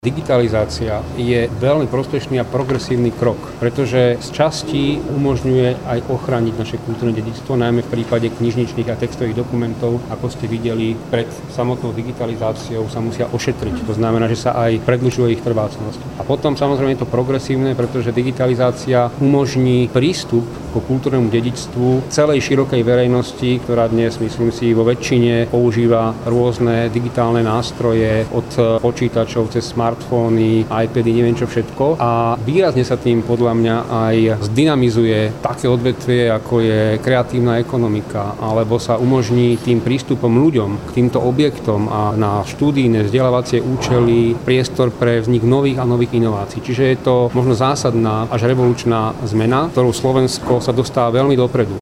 Slávnostné ukončenie tohto projektu sa konalo v piatok 16. októbra v Konzervačnom a digitalizačnom centre SNK.
3-minister-preco-je-dobra-digitalizacia.mp3